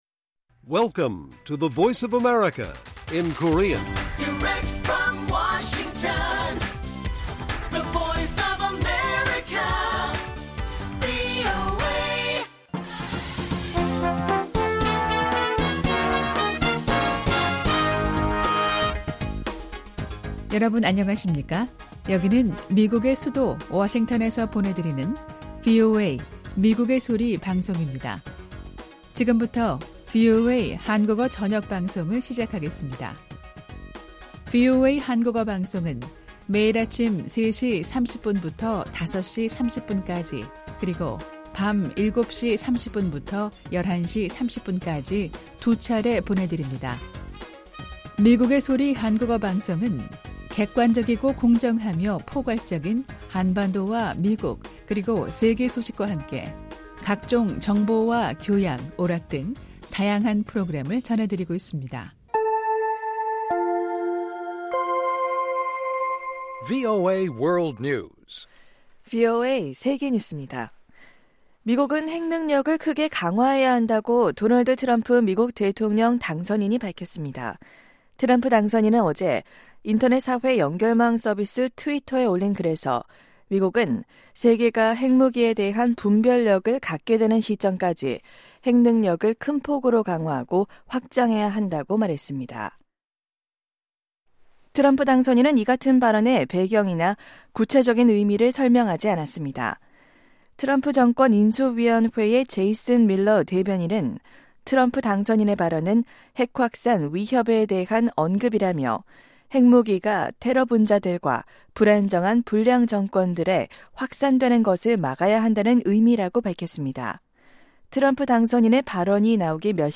VOA 한국어 방송의 간판 뉴스 프로그램 '뉴스 투데이' 1부입니다. 한반도 시간 매일 오후 8:00 부터 9:00 까지, 평양시 오후 7:30 부터 8:30 까지 방송됩니다.